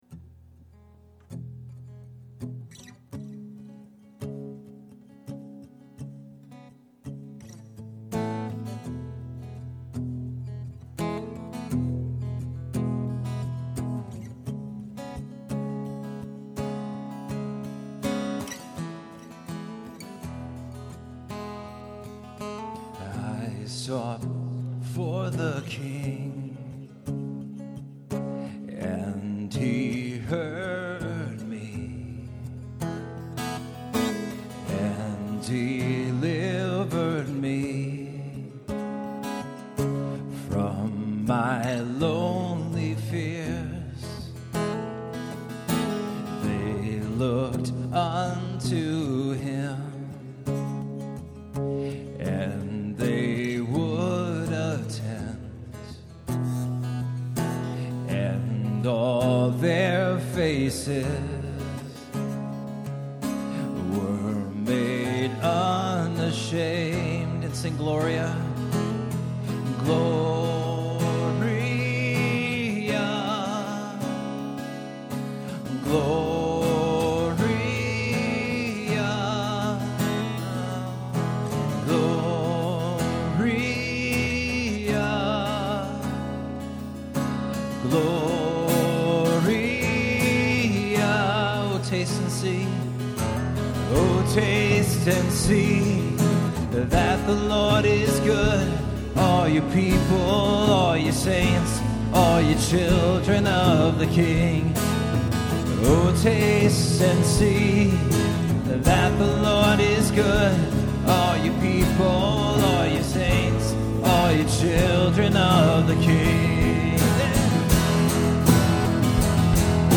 Performed live at Terra Nova - Troy on 8/16/09.